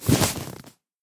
sounds / mob / horse / leather.mp3
leather.mp3